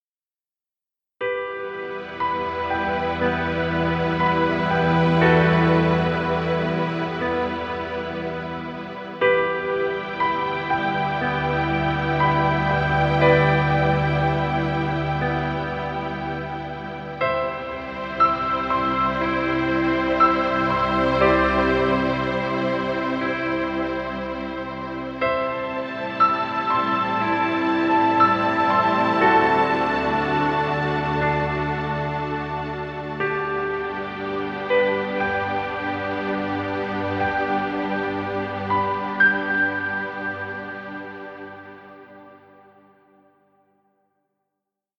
Piano music.
Stock Music.